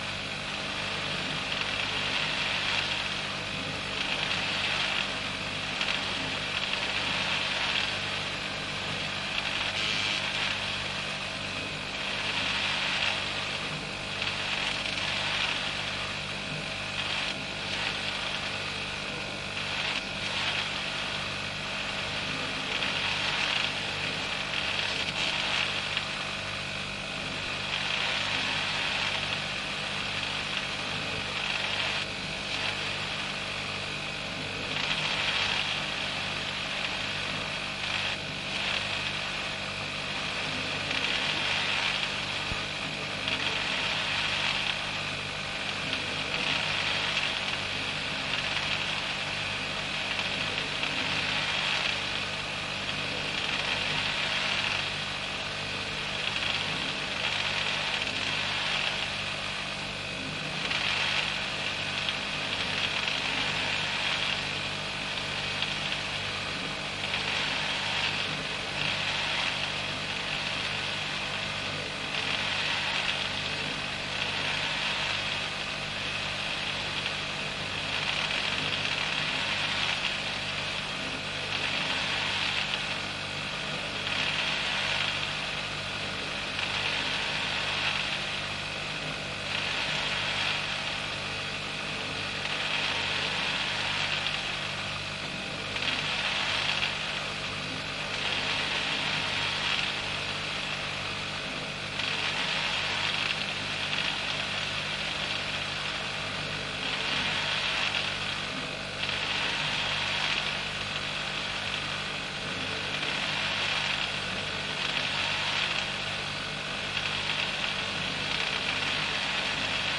吹风扇
描述：吹风扇，它在商业冷柜内吗？ 它在温室内吗？ 它是在工厂还是在仓库里？ 使用Zoom H4N录制。
标签： 冰箱 内部冰柜 温室 吹风扇 球迷 工厂 风扇 仓库
声道立体声